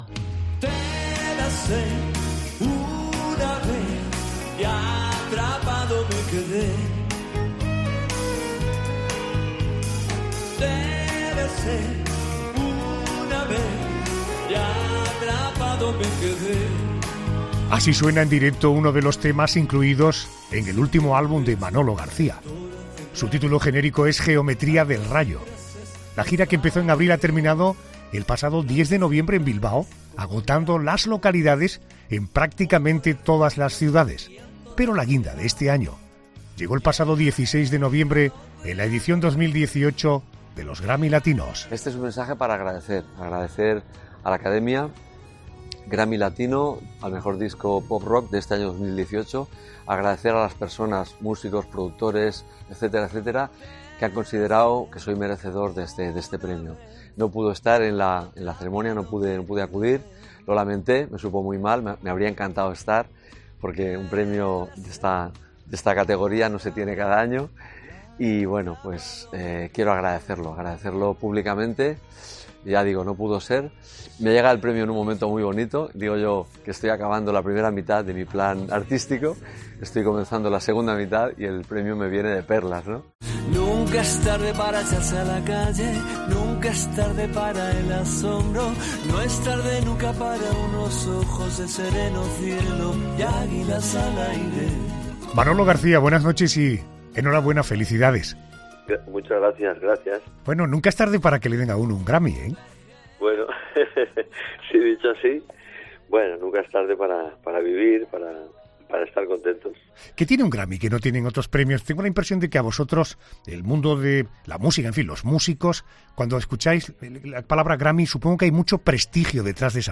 Manolo García, ganador del Grammy Latino a mejor álbum pop rock por 'Geometría del rayo', nos cuenta sus sensaciones en 'La Noche de COPE'